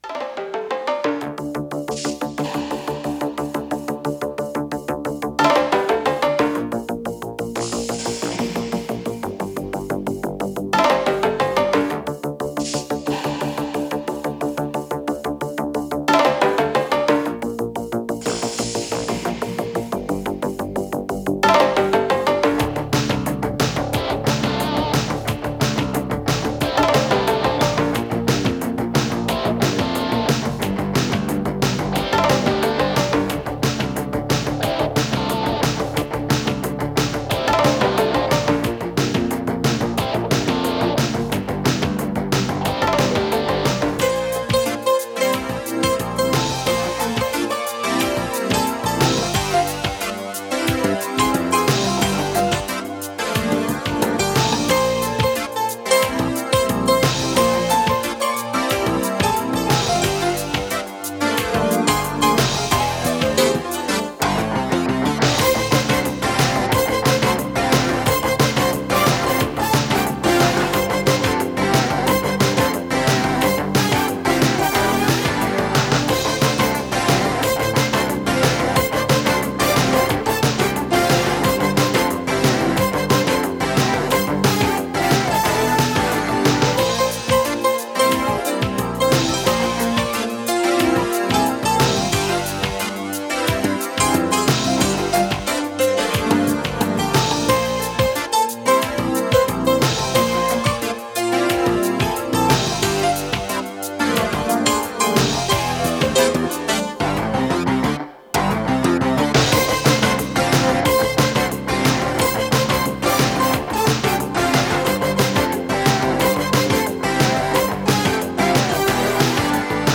с профессиональной магнитной ленты
ПодзаголовокИнструментальная пьеса
Скорость ленты38 см/с
ВариантДубль моно